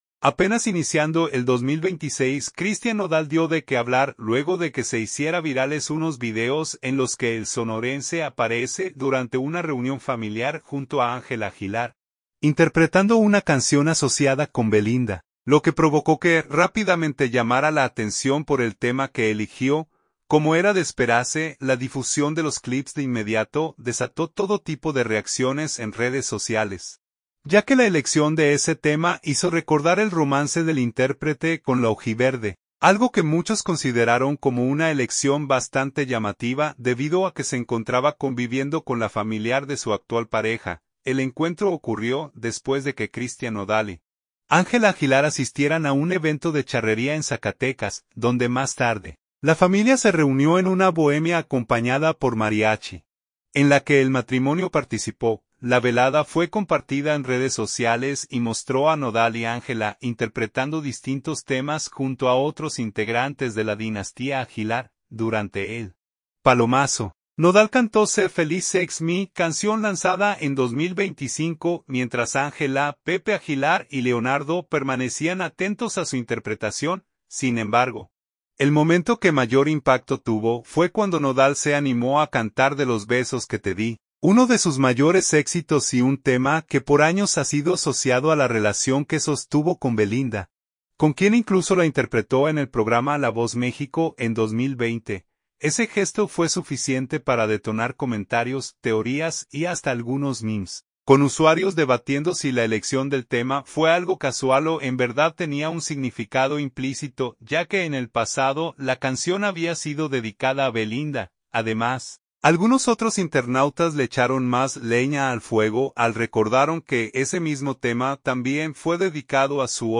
la familia se reunió en una bohemia acompañada por mariachi